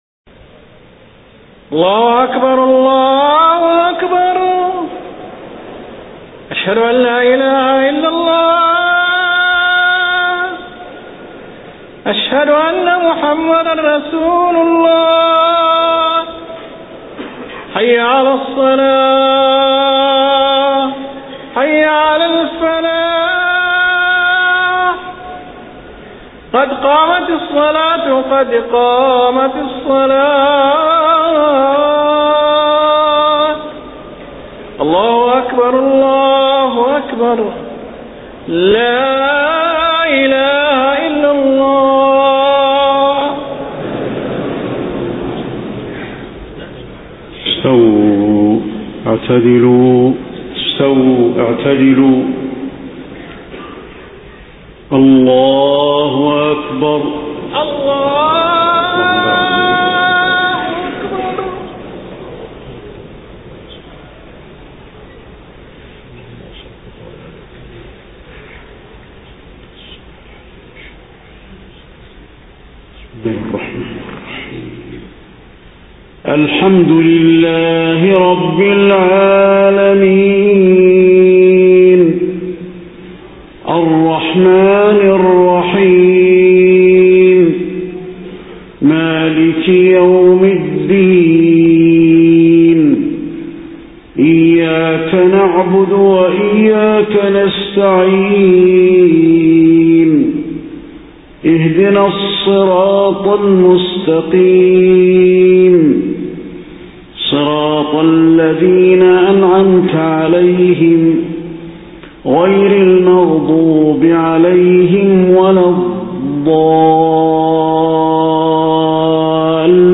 صلاة العشاء 10 ربيع الأول 1431هـ سورة النازعات كاملة > 1431 🕌 > الفروض - تلاوات الحرمين